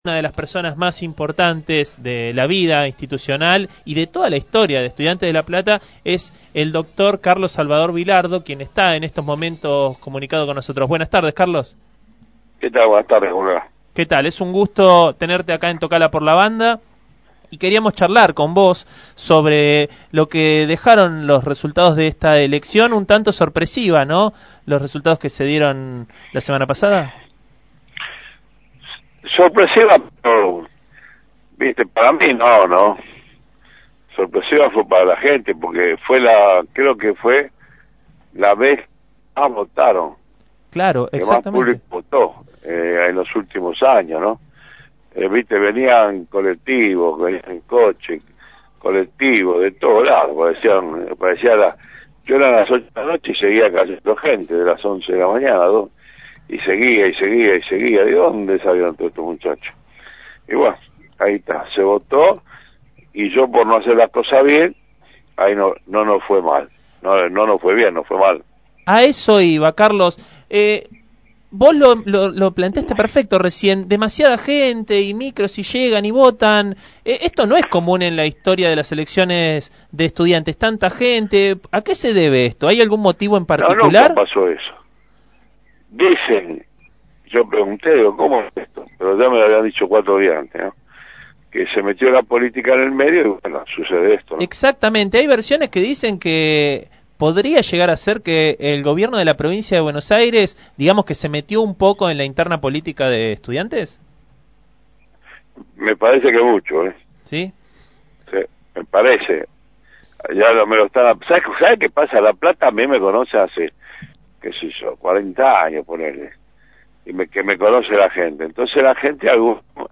Carlos Salvador Bilardo fue entrevistado en «Tocala x la Banda» y expresó sus dudas sobre las elecciones en Estudiantes de La Plata.